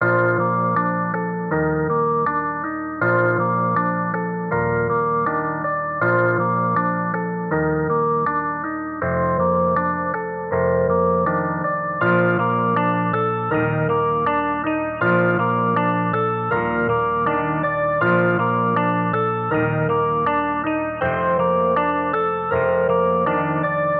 描述：Chiptune DnBLoop (seamless loop) 200bpm.
标签： loop electronic halftime drumnbass soundtrack game dnb chiptube
声道立体声